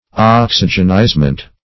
Oxygenizement \Ox"y*gen*ize`ment\, n.